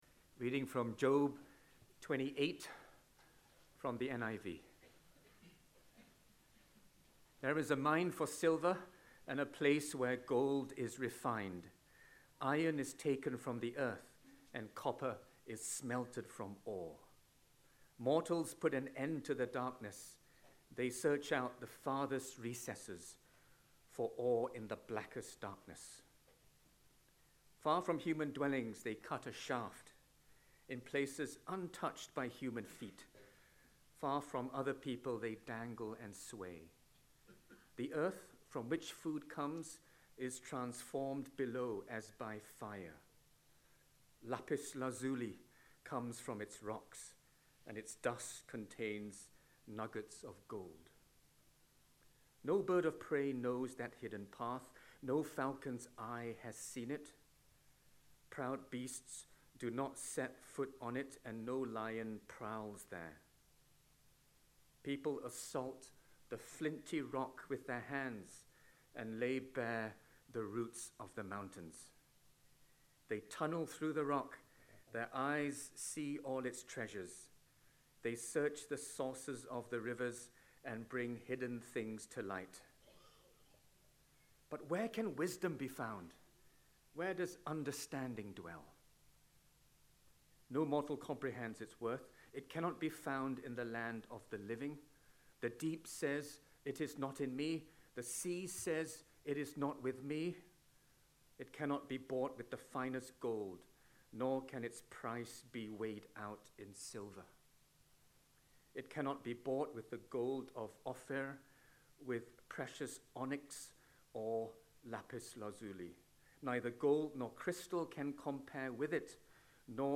Session 5 from the 2025 annual conference - Trauma: Christ's comfort in deep suffering